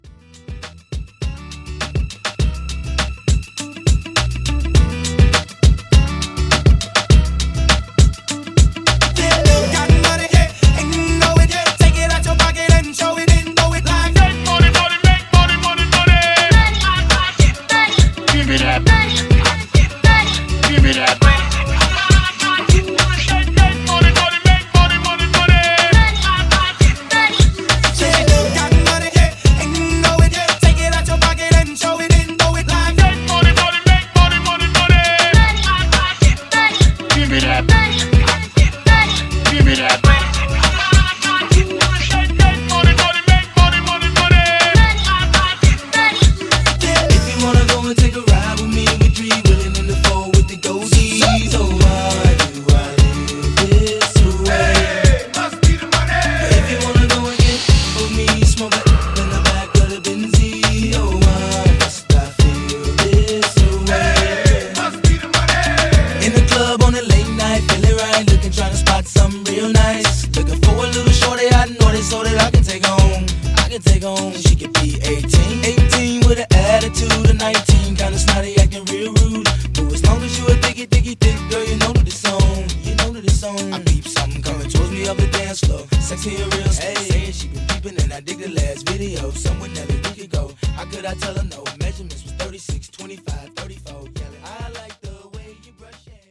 BPM: 106 Time